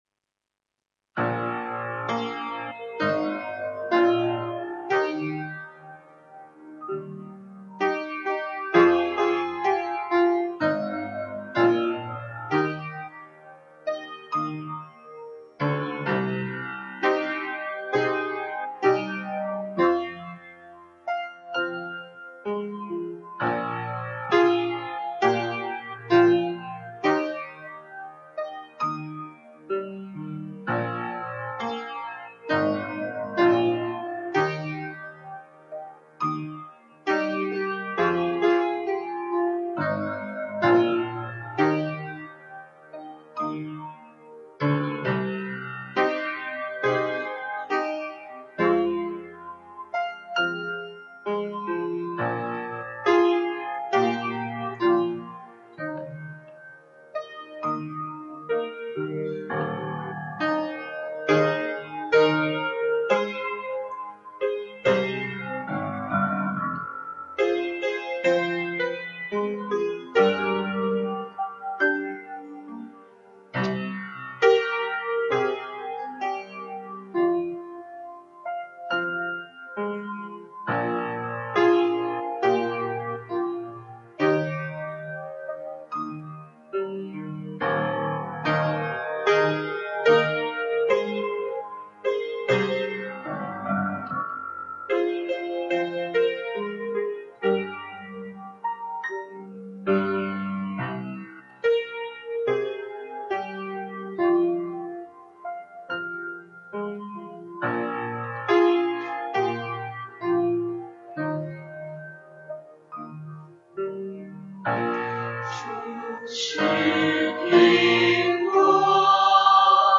撒种的心意 | 北京基督教会海淀堂